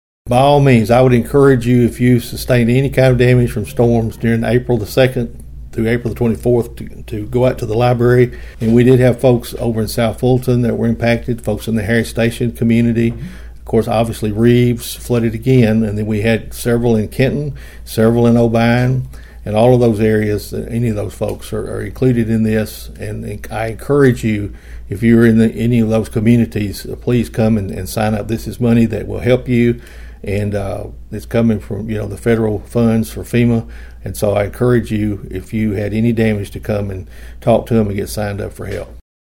Mayor Carr said local residents are urged to take advantage of the possible assistance that will be available.(AUDIO)